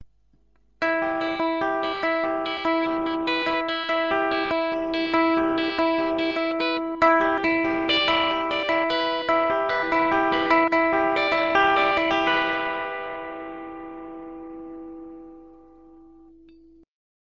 Zupfen
Hier werden die Saiten mit der Greifhand zu Akkorden oder Melodiephrasen gegriffen und von den Fingern der Spielhand gezupft.